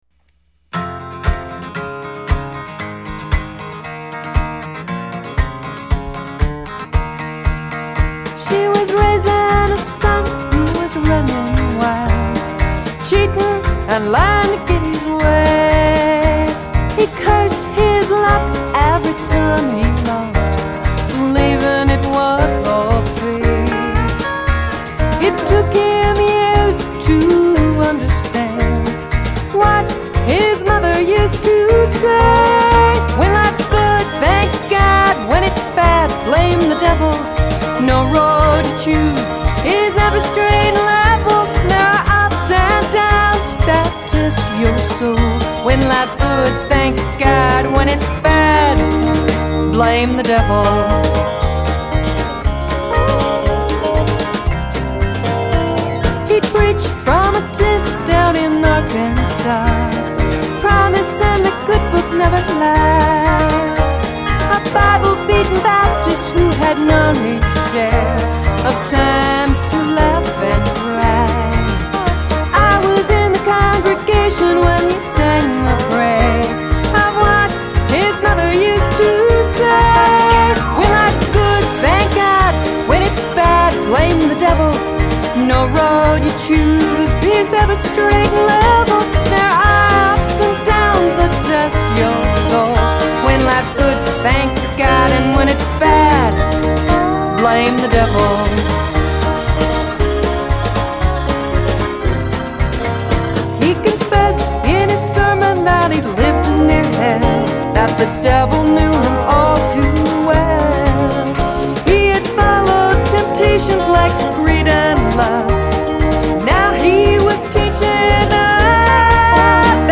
Final CD mix
Drums
Keyboards
Telecaster guitar, bass